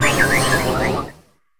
Cri de Terracruel dans Pokémon HOME.